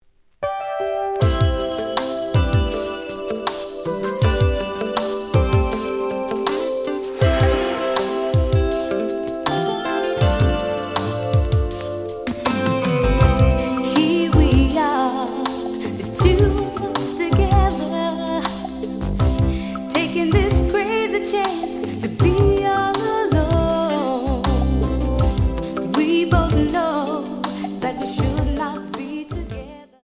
試聴は実際のレコードから録音しています。
●Genre: Soul, 80's / 90's Soul
●Record Grading: VG+ (両面のラベルに若干のダメージ。多少の傷はあるが、おおむね良好。)